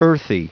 Prononciation du mot earthy en anglais (fichier audio)
Prononciation du mot : earthy